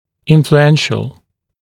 [ˌɪnflu’enʃl][ˌинфлу’эншл]влиятельный, важный